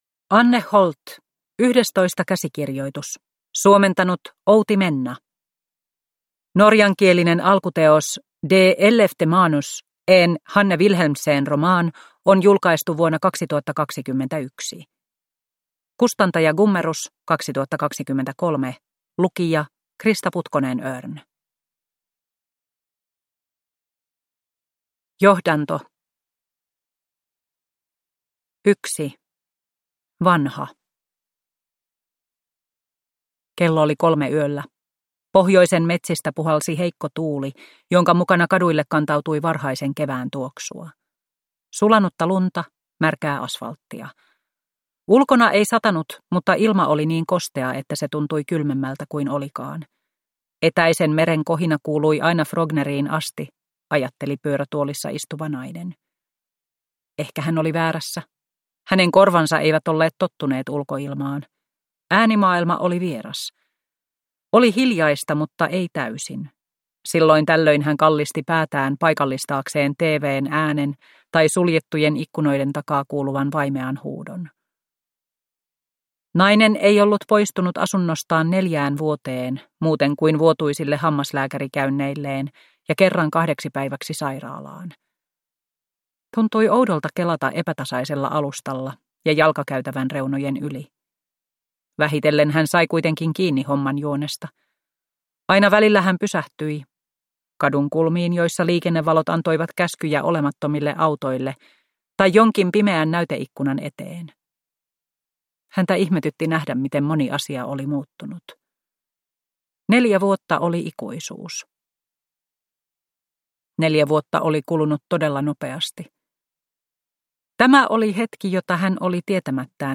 Yhdestoista käsikirjoitus – Ljudbok – Laddas ner